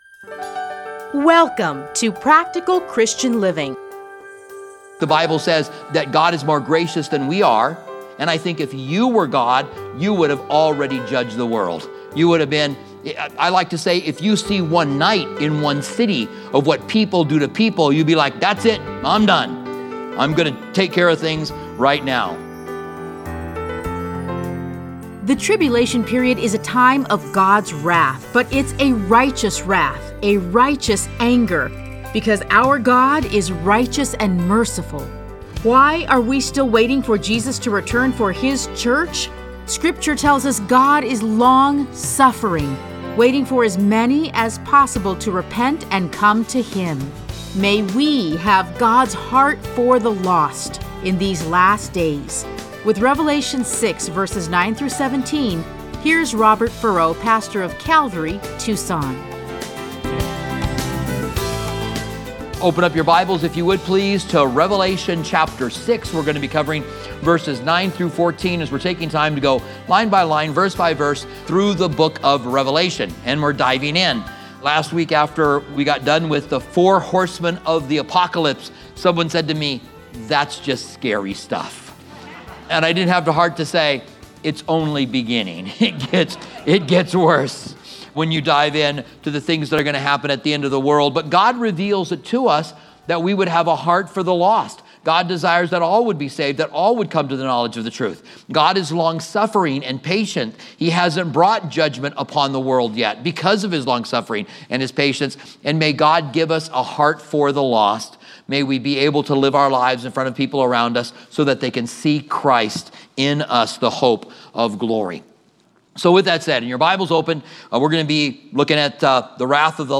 Listen to a teaching from Revelation 6:9-17.